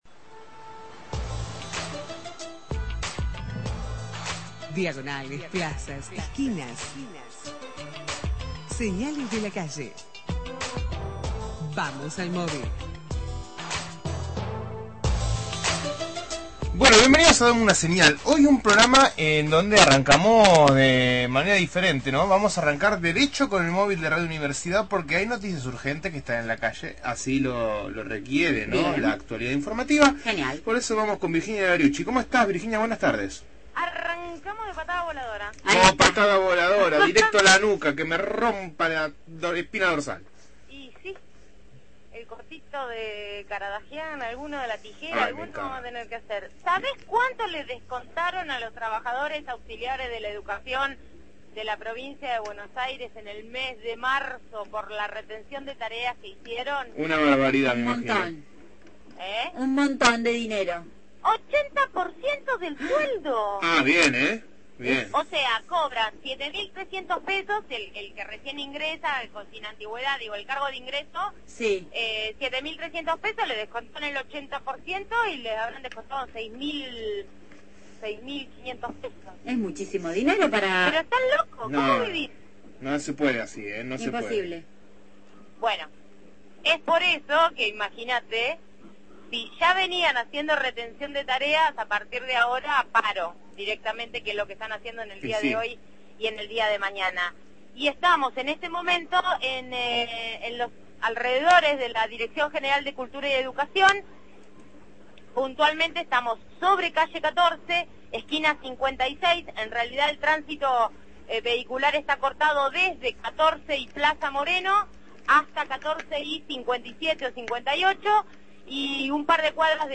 MÓVIL/ Reclamos de auxiliares docentes por descuentos salariales – Radio Universidad